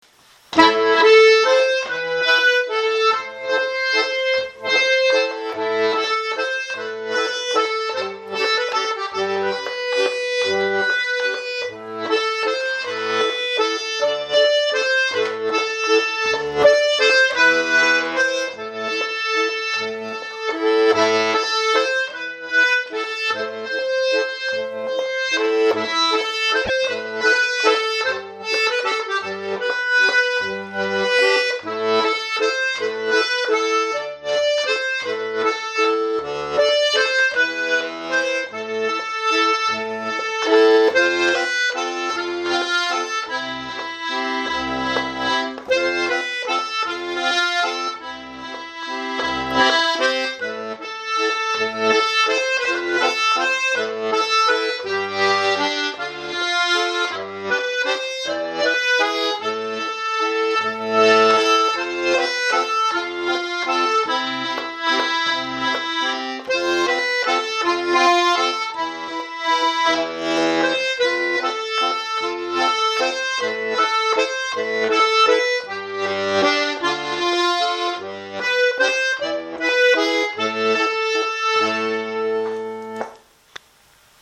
Valzer in gennaio diato.MP3